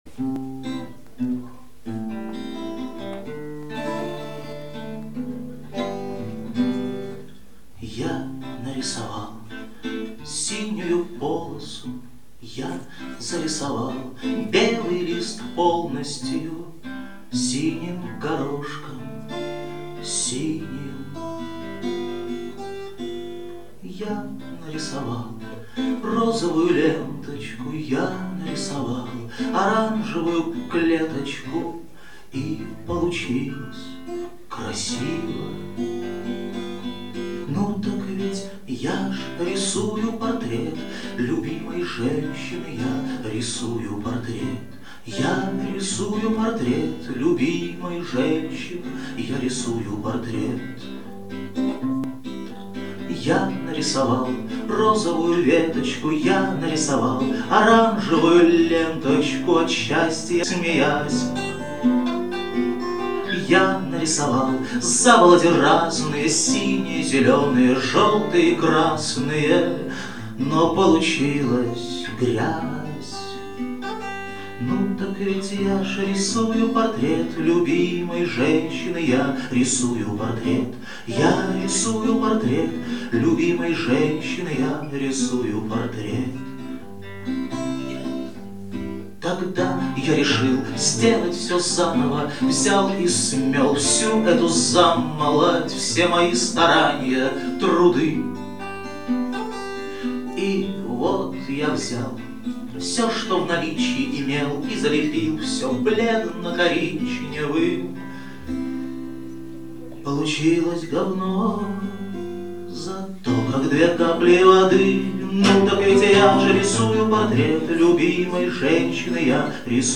Запись первых трёх часов "Праздника Самой Длинной Ночи" в ЦАПе 22.12.2002